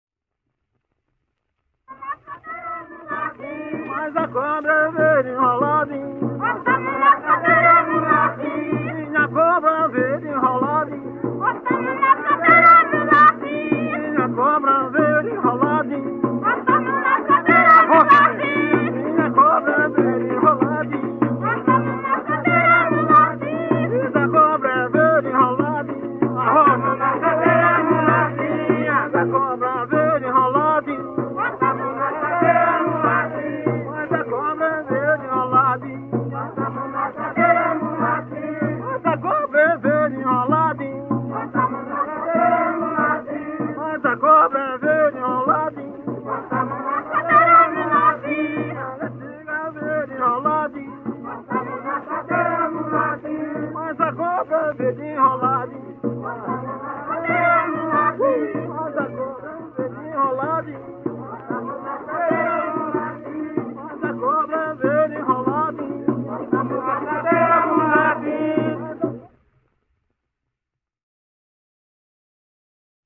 Coco de roda -""Olha a cobra verde""